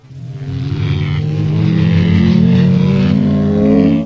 StunChrg.ogg